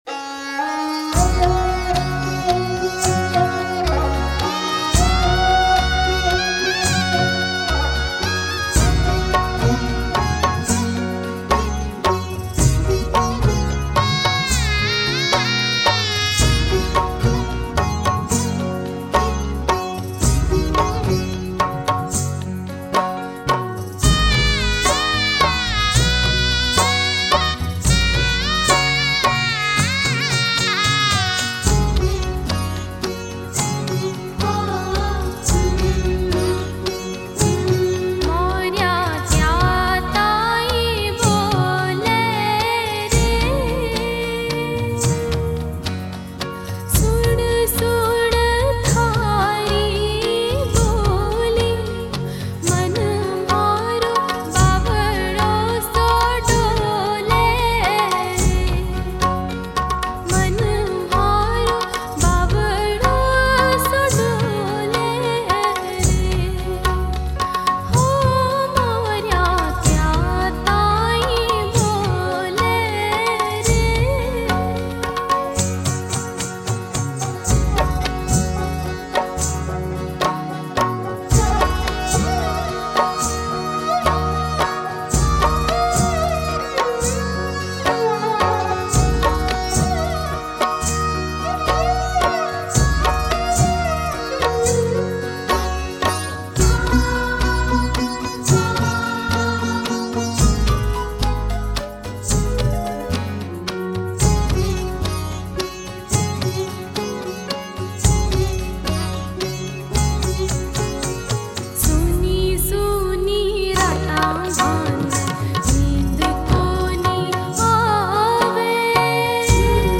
Folk